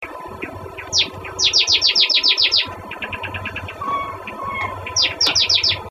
チョッチョチョチョチョチョチョ クゥーックゥーッ生き残った文鳥はさえずりが綺麗
実は変わっているらしい彼のさえずり